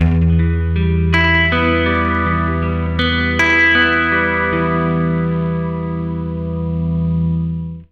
80MINARP E-R.wav